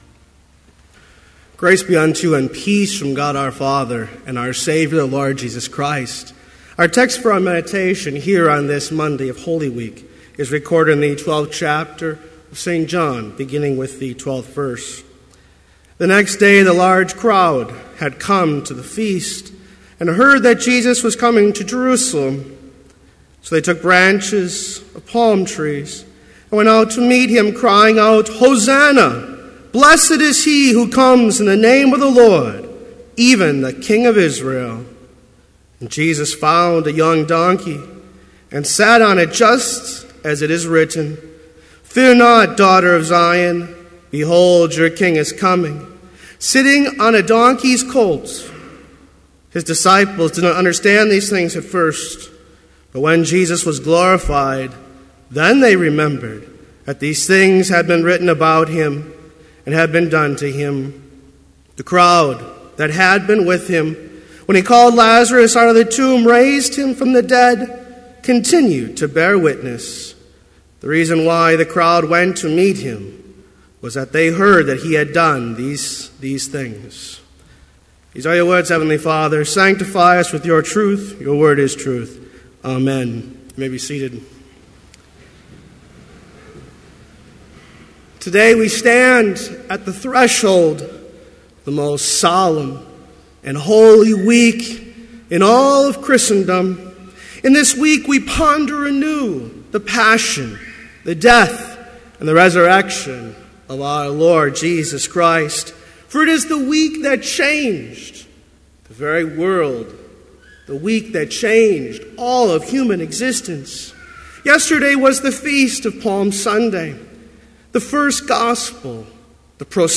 Complete service audio for Chapel - April 14, 2014